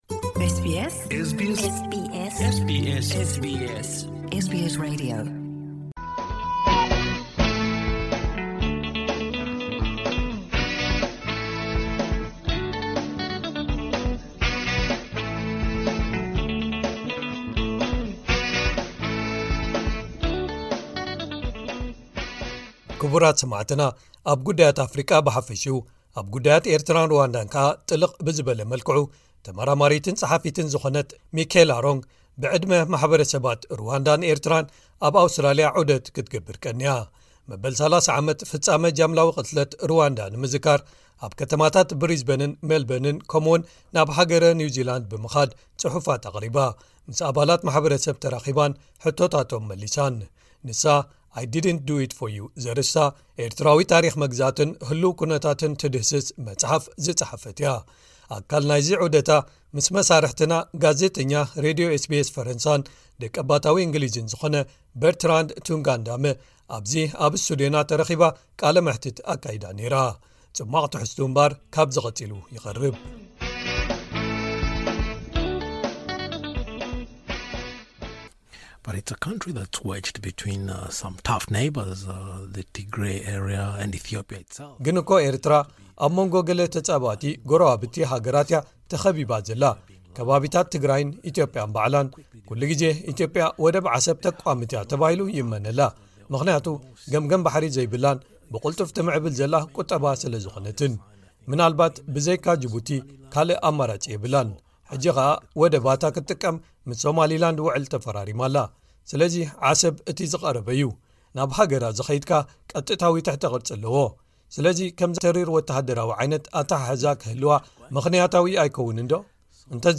ኣብ ጉዳያት ኣፍሪቃ ብሓፈሽኡ፡ ኣብ ጉዳያት ኤርትራን ሩዋንዳን ከኣ ከኣ ጥልቕ ብዝበለ መልክዑ፡ ተመራማሪትን ጸሓፊትን ዝኾነት ሚከላ ሮንግ መበል 30 ዓመት ፍጻመ ጃምላዊ ቅትለት ሩዋንዳ ንምዝካር ኣብ ኣውስትራልያ ዑደት ክትገብር ቀንያ። ንሳ 'I didn't do it for you' ዘርእስታ ኤርትራዊ ታሪኽ መግዛእትን ትድህስ ስ መጽሓፍ ዝጸሓፈት'ያ። ኣብ መንጎ’ዚ ዑደታ ምስ ኤስቢኤስ ኣብ'ዚ ኣብ ስቱድዮና ተረኺባ ቃለመሕትት ኣካይዳ ኔራ። ገለ ካብ ጽሟቕ ትሕዝትኡ ክቐርብ’ዩ። (2ይን መወዳእታ ክፋል)